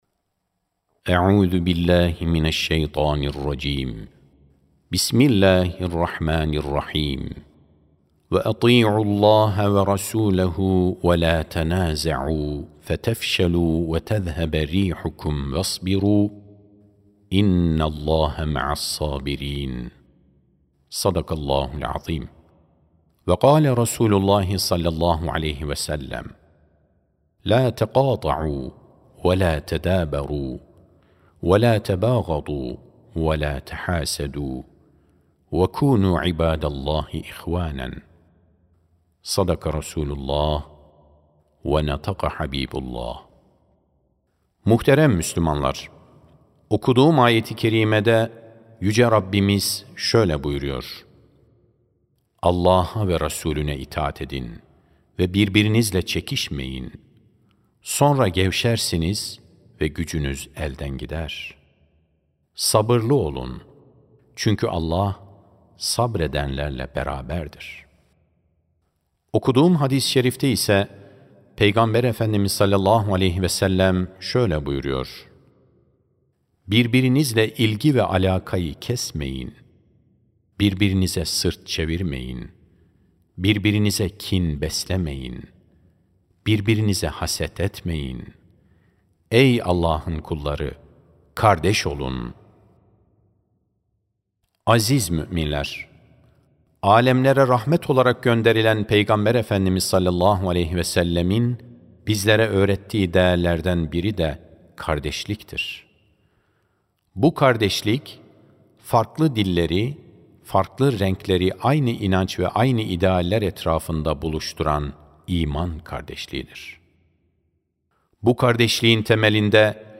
24 Ekim 2025 Tarihli Cuma Hutbesi
Sesli Hutbe (Birlikte Bereket, Kardeşlikte Rahmet Vardır).mp3